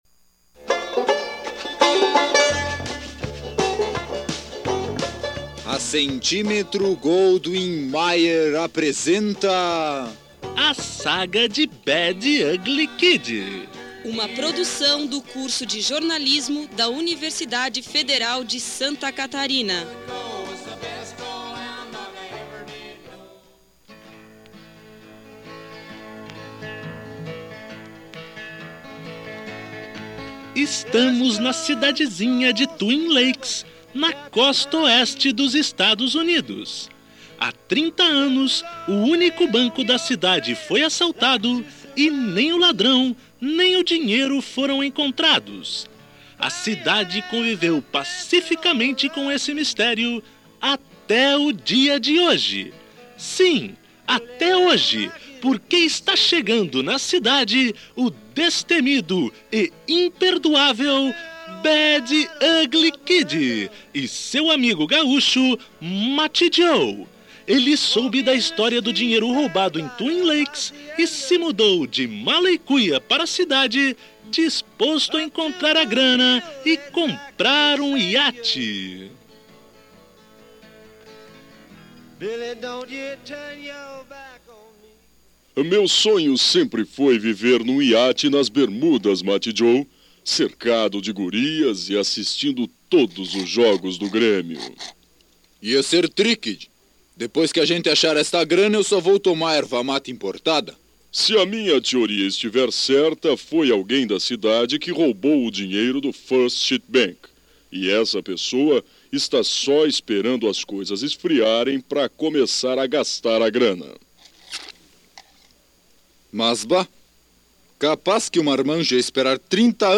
Uma história de faroeste, com direito a mocinhos, bandidos e um sotaque conhecido no sul do Brasil.
Radioteatro